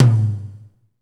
TOM A C M0XR.wav